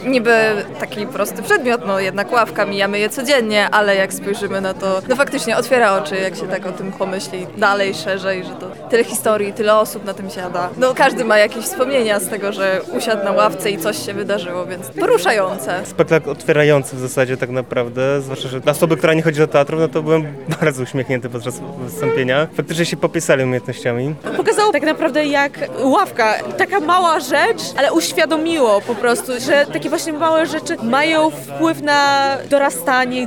O wrażenia po spektaklu zapytaliśmy także publiczność:
sonda
sonda-lawka.mp3